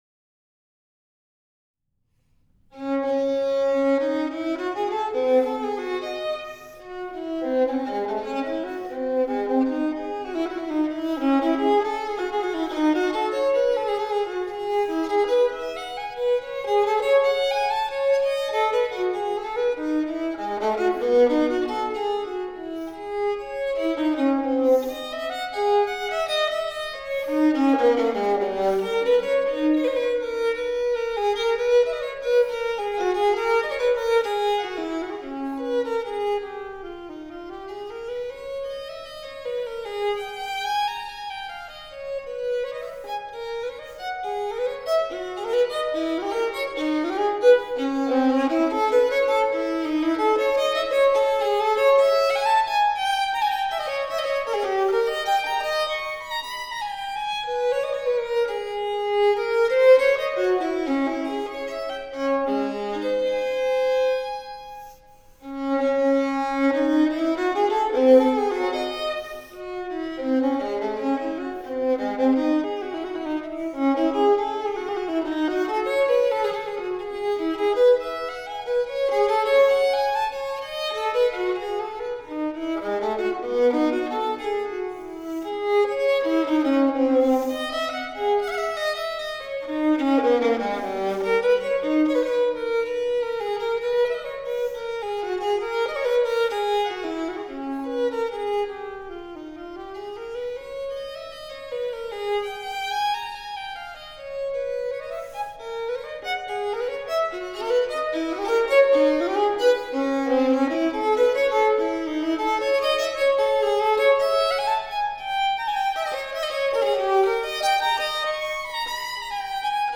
Esta es la primera danza de la Partita en re m para violín solo BWV 1004 del compositor alemán J.S. Bach.
Aquí tenéis dos versiones interesantes de audio, de Kuijken y  Lucy van Dael,  con violín barroco (la versión de Kuijken es diferente a la anterior):